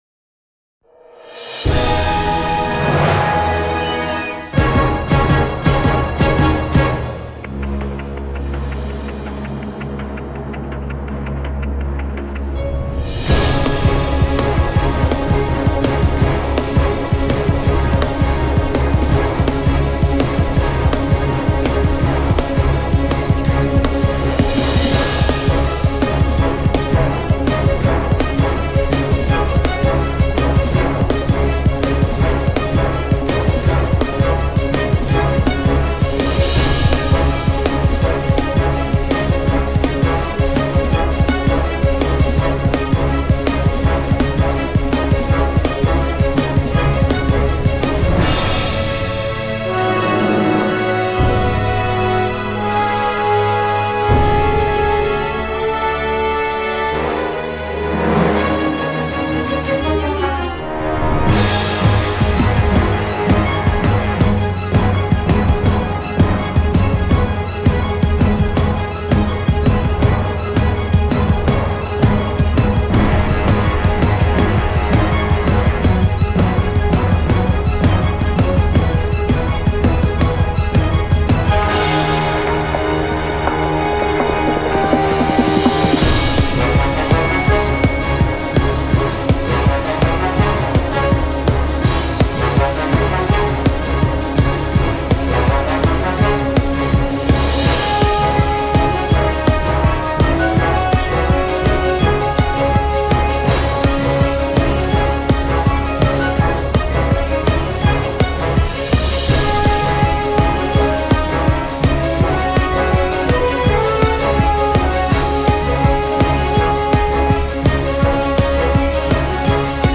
熱く燃えたぎるような戦闘系楽曲。 ハリウッドのアクション映画で流れそうな、エピック的な要素もあったり。